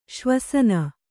♪ śvasana